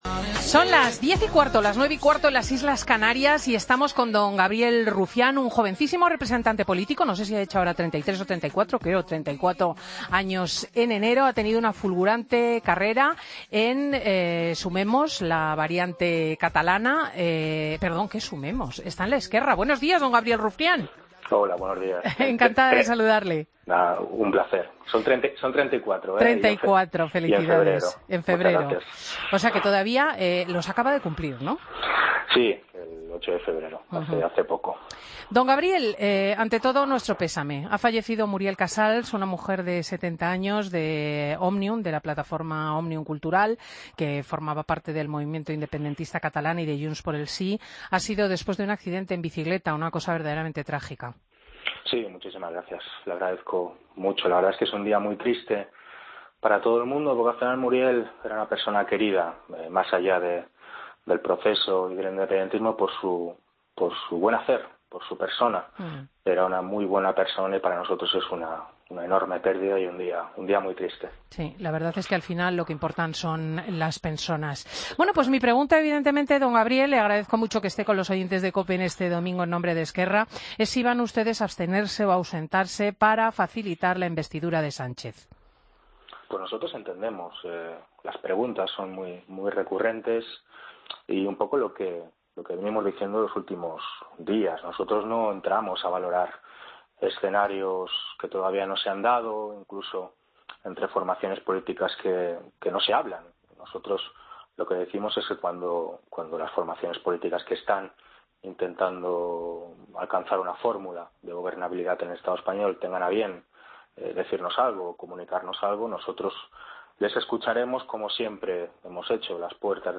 AUDIO: Escucha la entrevista a Gabriel Rufián, Portavoz adjunto de ERC en el Congreso de los Diputados, en Fin de Semana de Cope.